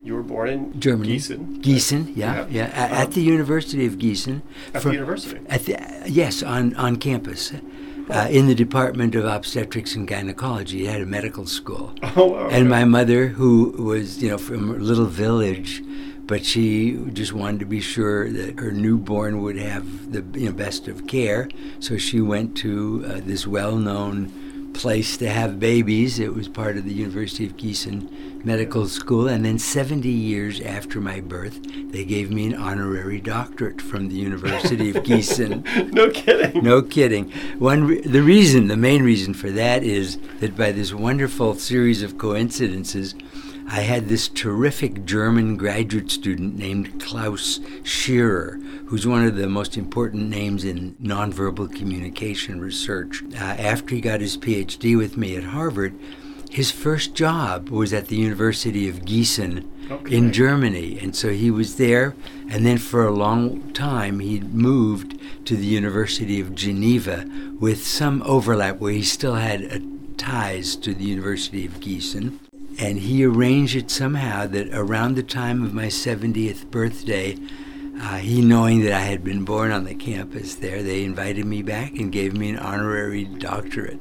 I am pleased to present some tidbits from our conversation — all recalled by Dr. Rosenthal with his characteristic kindness and joviality.